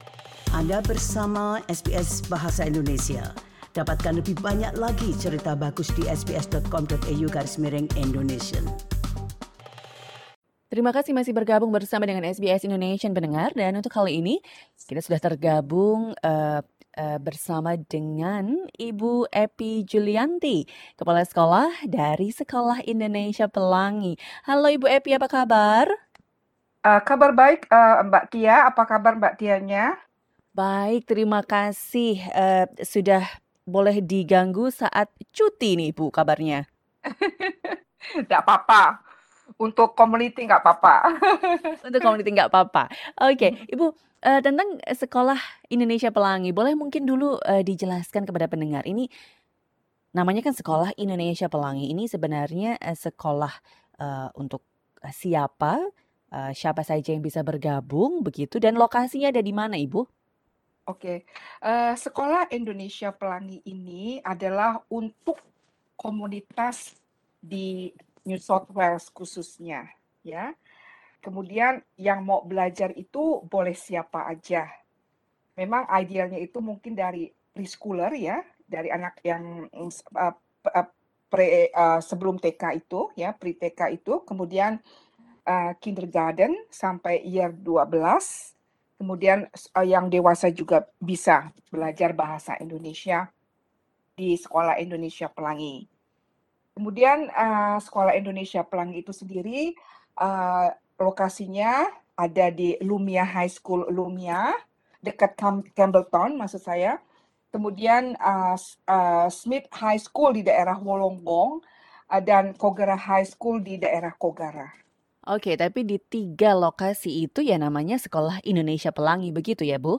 Listen to the full interview on the SBS Indonesian's podcast.